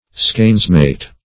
Skainsmate \Skains"mate`\, n.